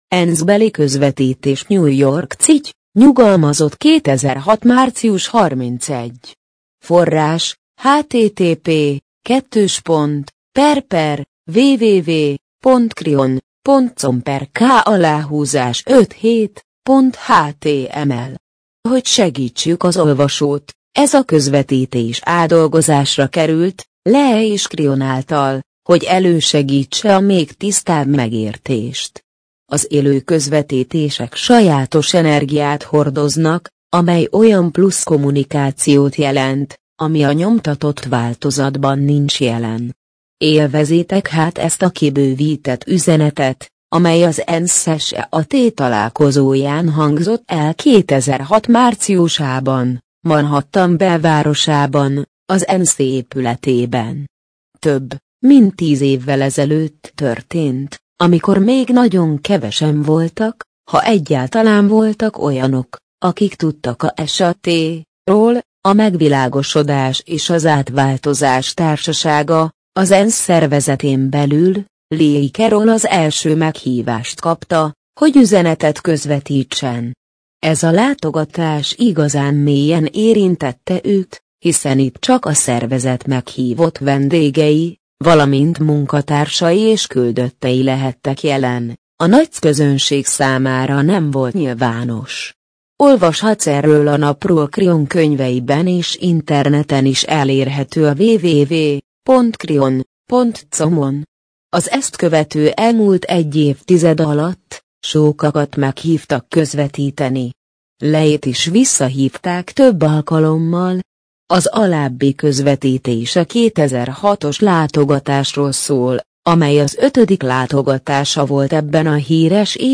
MP3 gépi felolvasás ENSZ-beli közvetítés - 2006 ENSZ-beli közvetítés New York City, NY - 2006. március 31.